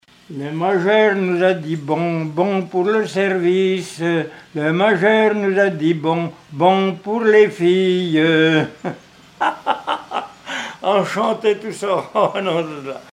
Chants brefs - Conscription
gestuel : à marcher
Pièce musicale inédite